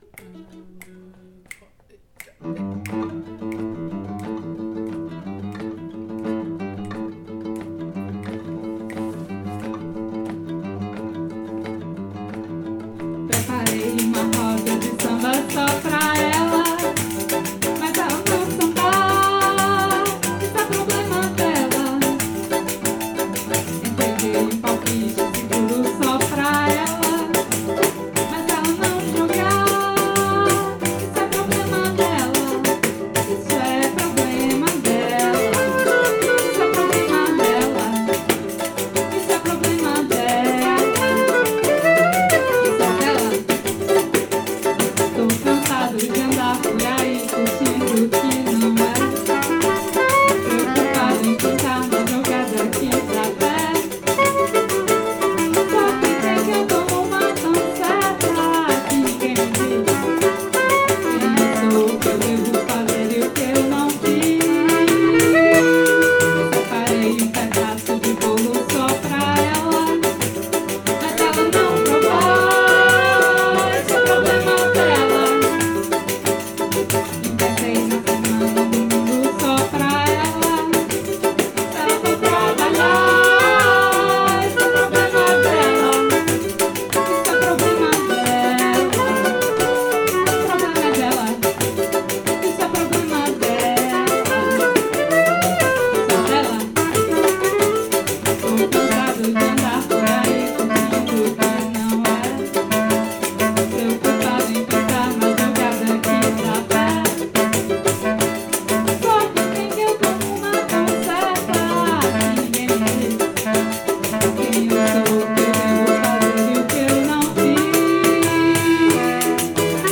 Rec atelier